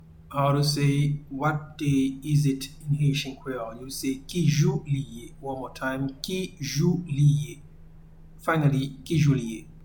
Pronunciation and Transcript:
What-day-is-it-in-Haitian-Creole-Ki-jou-li-ye.mp3